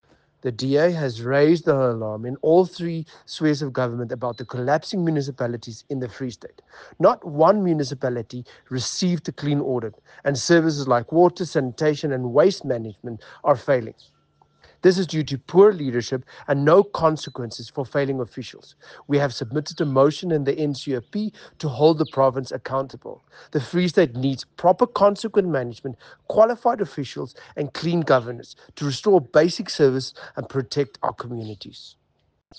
Afrikaans soundbites by Igor Scheurkogel MP, and